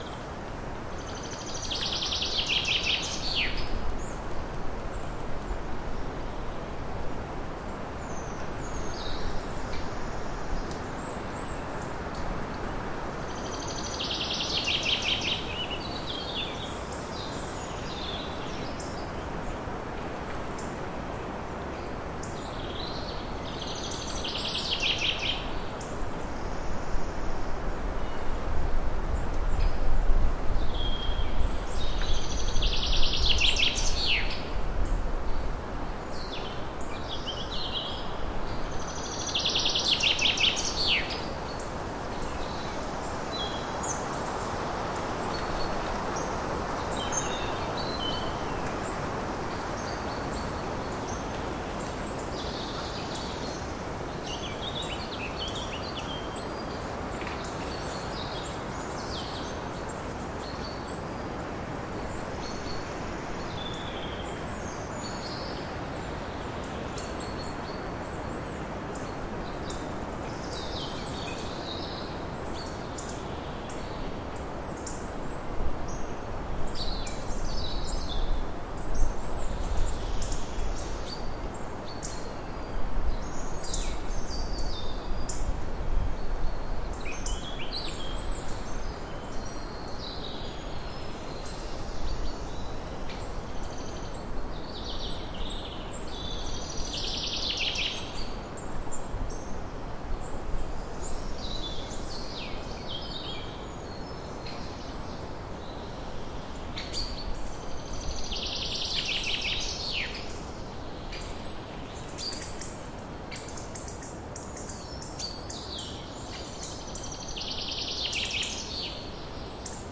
你可以听到树木在风中移动，还有许多不同鸟类的鸟鸣。
Tag: 鸟鸣声 的Steigerwald Oberfranken 性质 现场记录 巴伐利亚